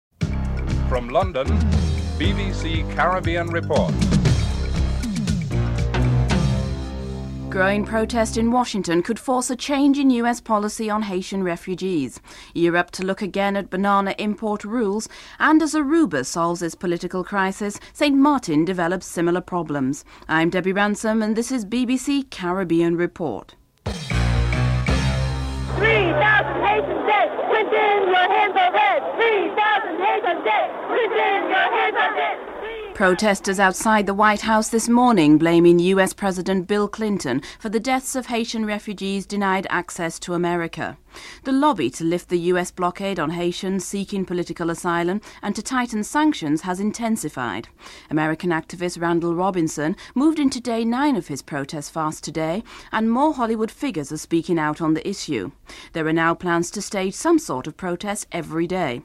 9. Wrap up & theme music (14:29-14:43)